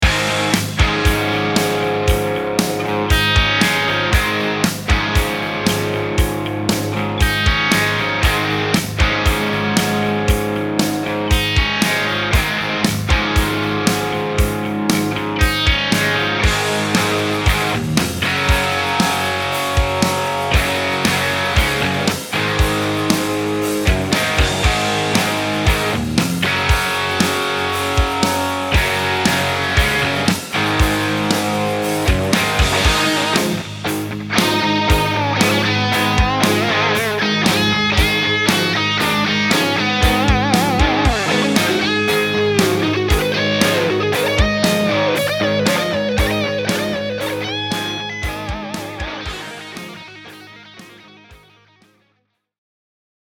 Il humbucker Riff Raff ha una risposta dei bassi serrata e incisiva con un morso morbido nella fascia alta; l'epitome della voce del rock'n'roll dei primi anni '60.
Sebbene doppiato come un humbucker rock vintage, i musicisti hard rock e metal apprezzeranno il suono incisivo e concentrato se usato con rig ad alto guadagno.